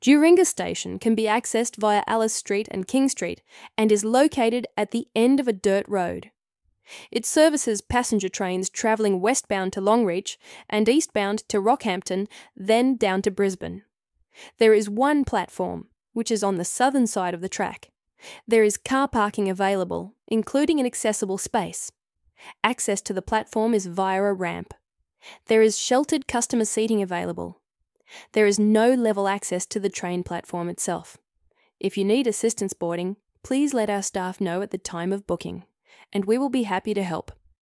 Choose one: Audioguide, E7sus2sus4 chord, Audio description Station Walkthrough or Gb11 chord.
Audio description Station Walkthrough